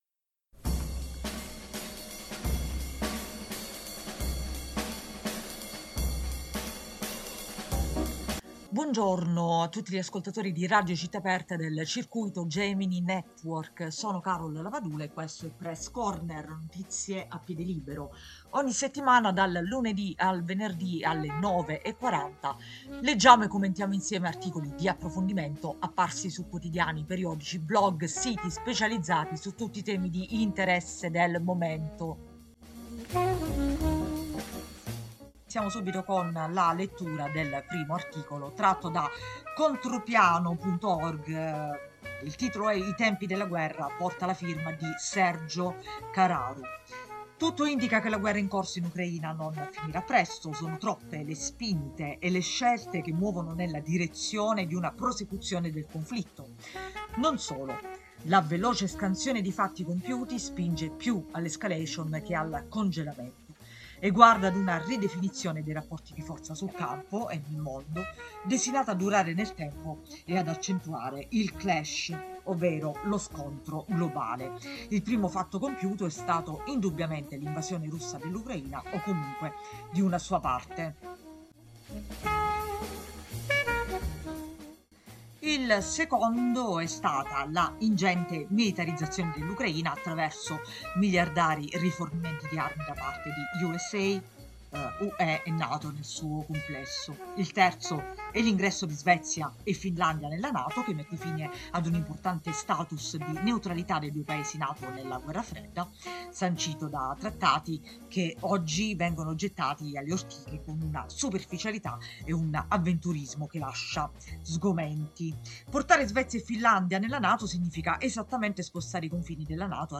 Leggiamo e commentiamo insieme articoli di approfondimento apparsi su quotidiani, periodici, blog, siti specializzati su tutti i temi di interesse del momento.